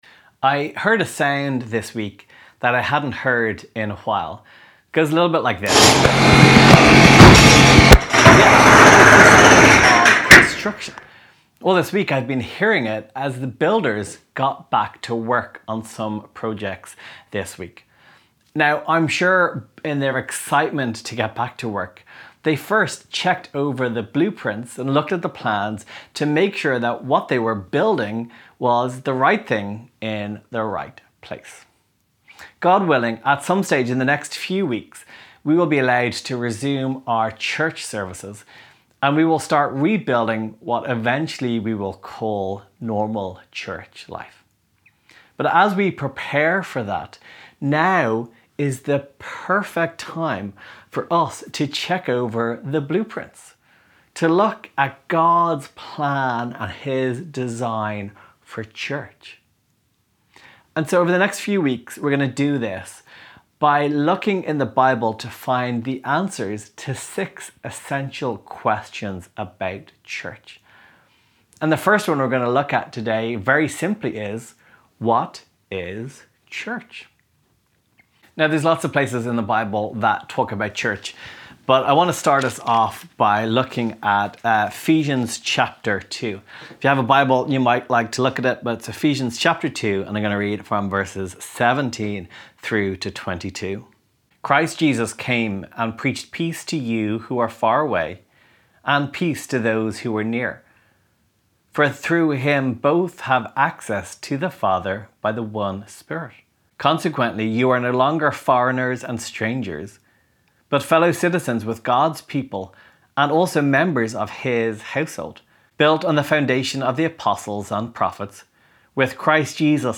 Downloads Download ‘What is Church?’ Sermon MP3 *NEW* 1 Corinthians Reading Plan Share this: Share on X (Opens in new window) X Share on Facebook (Opens in new window) Facebook Like Loading...